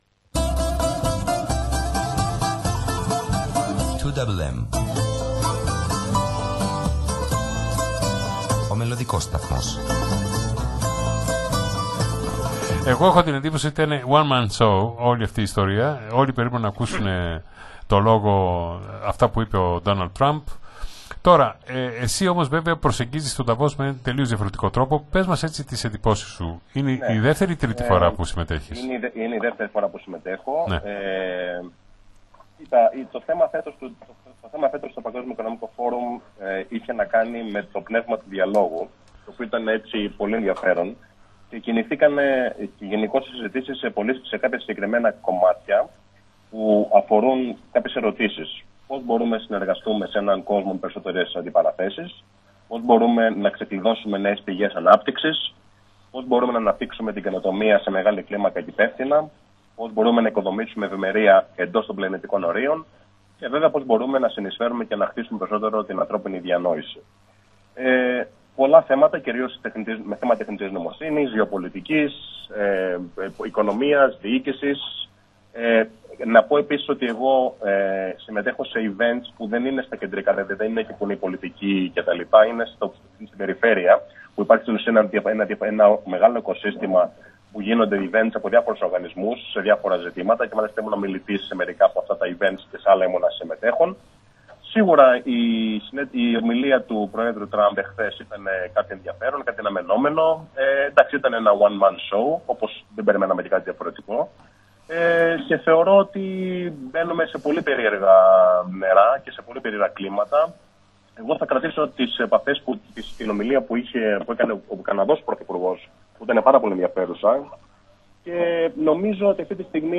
μίλησε ζωντανά